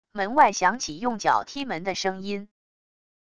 门外响起用脚踢门的声音wav音频